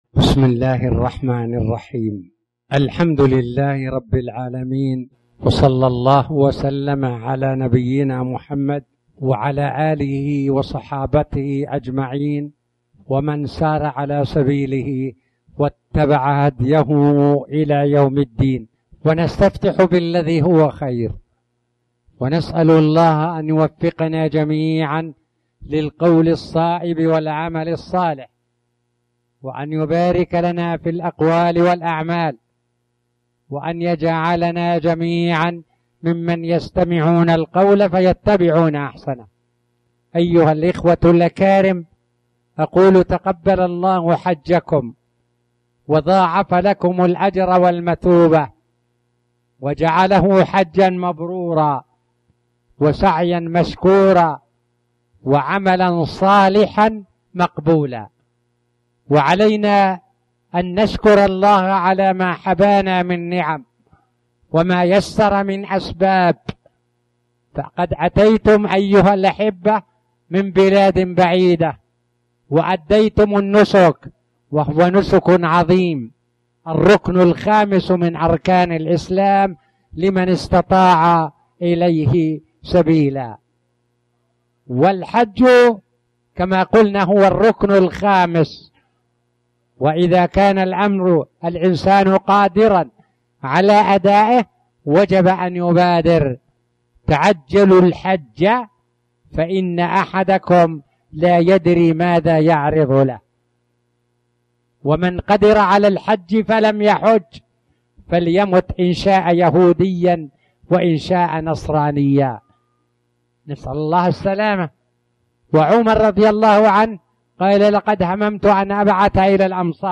تاريخ النشر ٢٩ ذو الحجة ١٤٣٨ هـ المكان: المسجد الحرام الشيخ